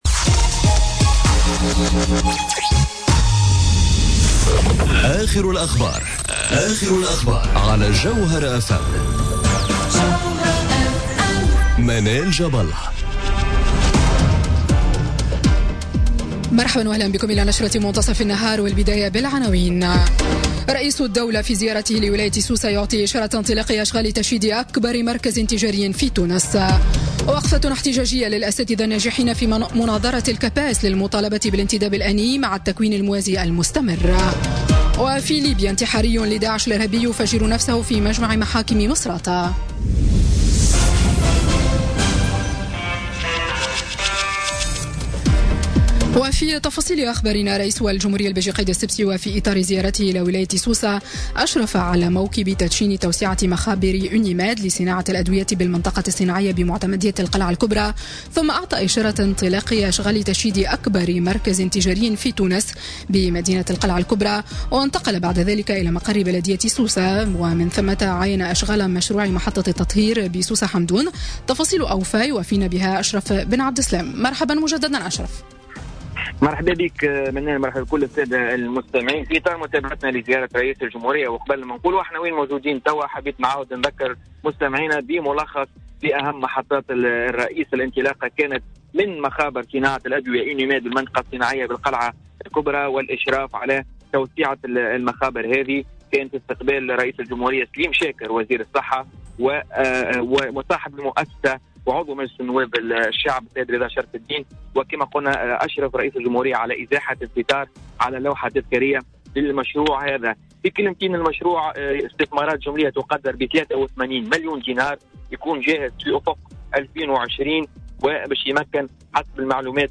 نشرة أخبار منتصف النهار ليوم الإربعاء 4 أكتوبر 2017